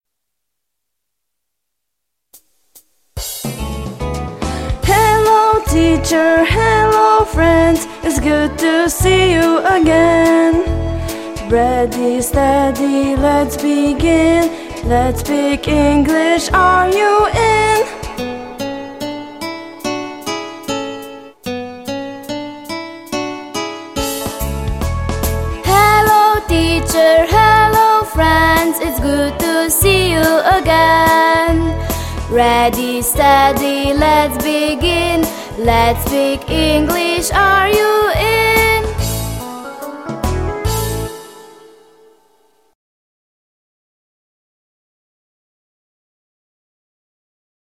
Pesem
otroški glasovi